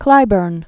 (klībərn), Harvey Lavan Known as "Van." 1934-2013.